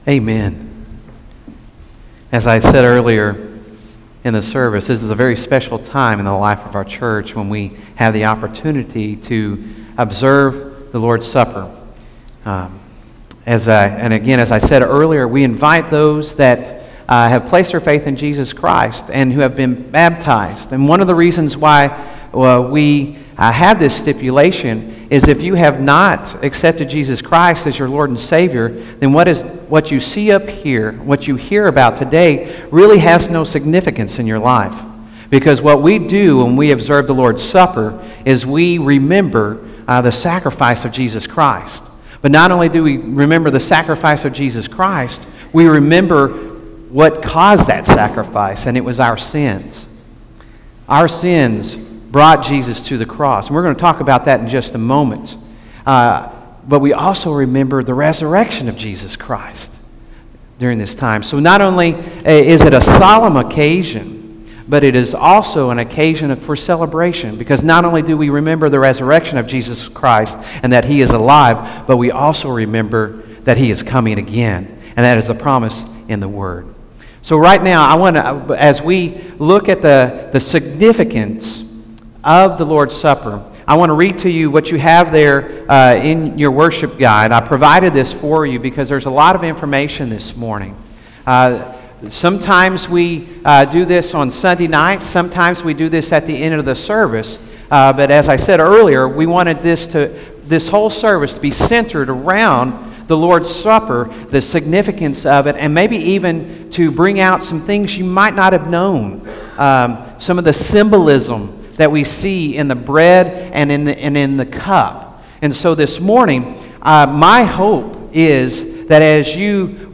by Office Manager | May 23, 2016 | Bulletin, Sermons | 0 comments